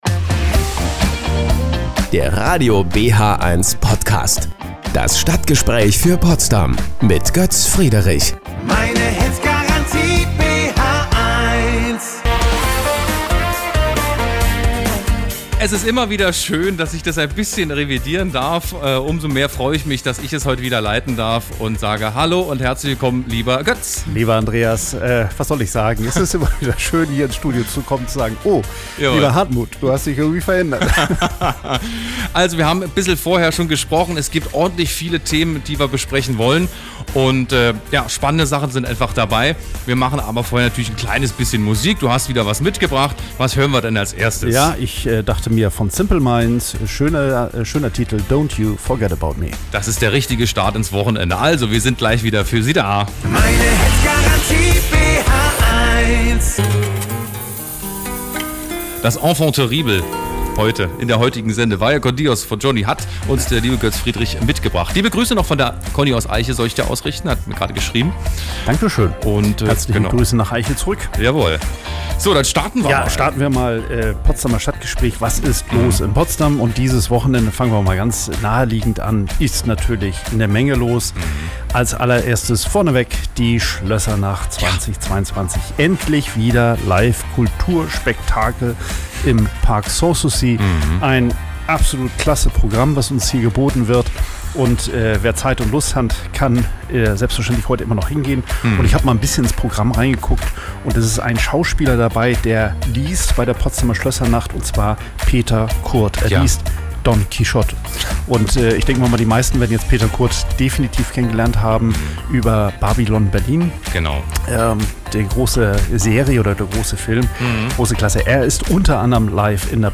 Ein Interview bei Radio BHeins